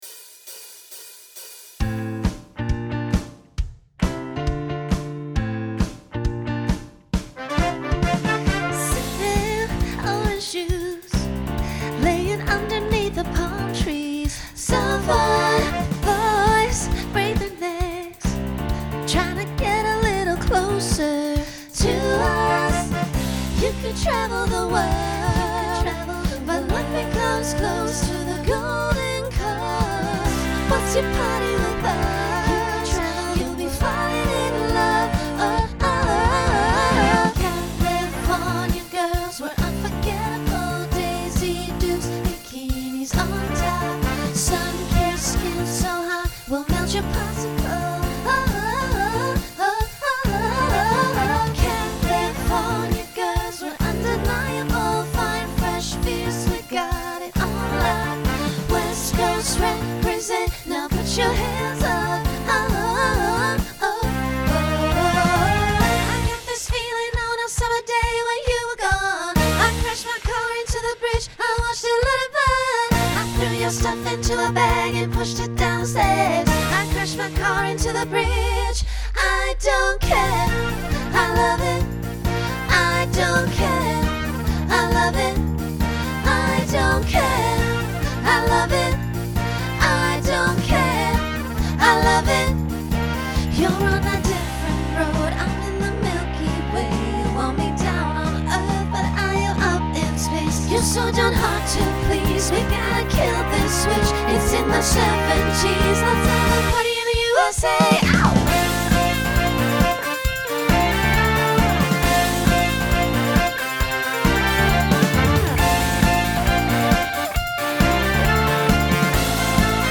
Genre Pop/Dance Instrumental combo
Voicing SSA